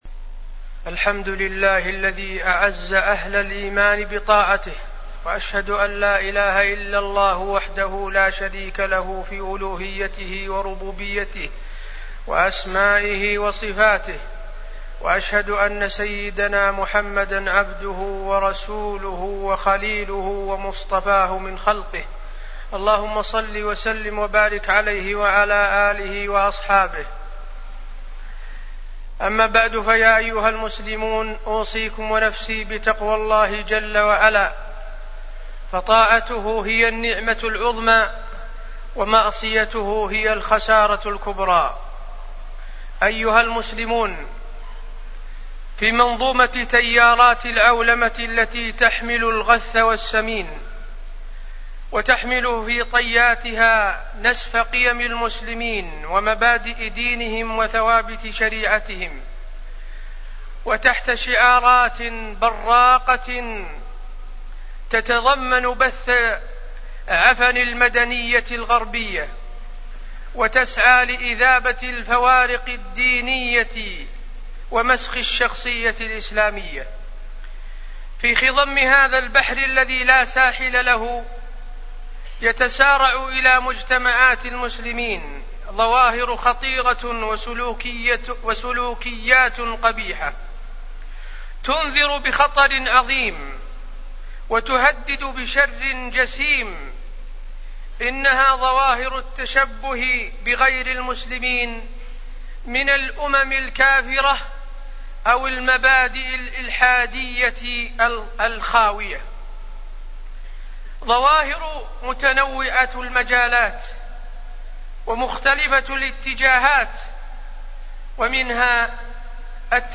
تاريخ النشر ٢٠ جمادى الأولى ١٤٣٠ هـ المكان: المسجد النبوي الشيخ: فضيلة الشيخ د. حسين بن عبدالعزيز آل الشيخ فضيلة الشيخ د. حسين بن عبدالعزيز آل الشيخ التشبه بالكفار